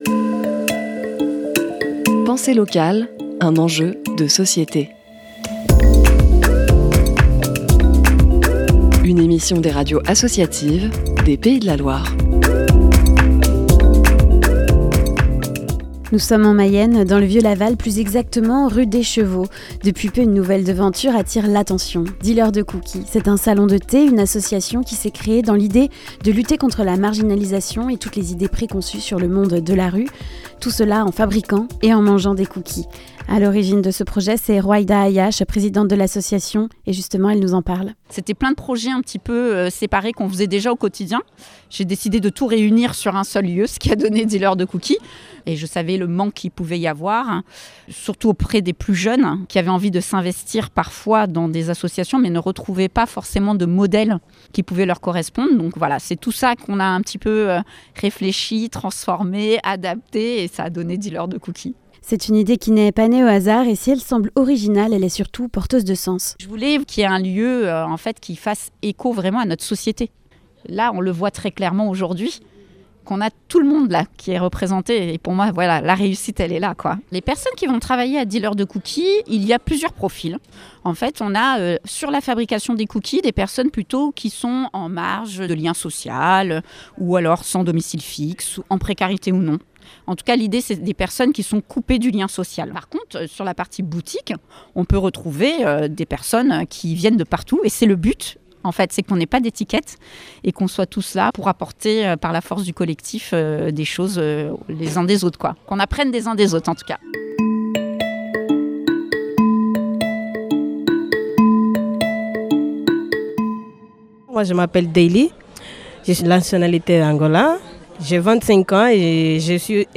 Reportage-Dealer-De-Cookie-en-Mayenne-LAutre-Radio1.mp3